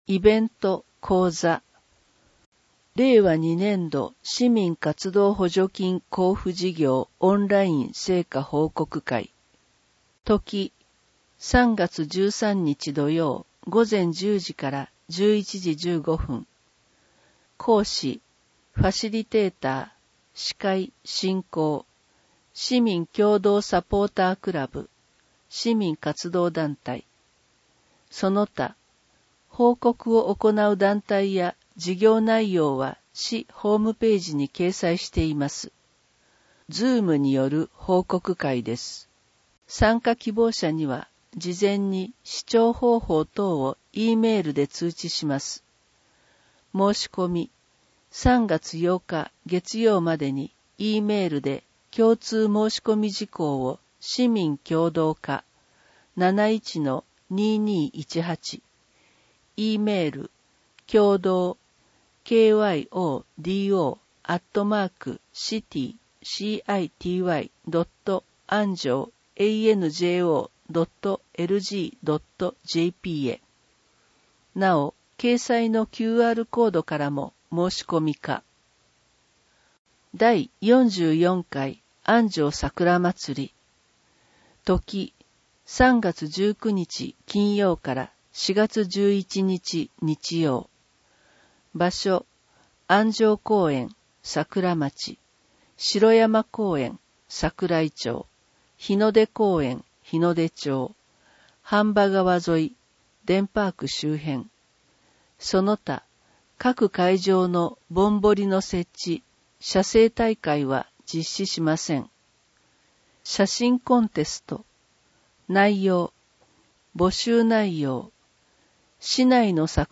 広報あんじょうの音声版を公開しています
なお、以上の音声データは、「音訳ボランティア安城ひびきの会」の協力で作成しています。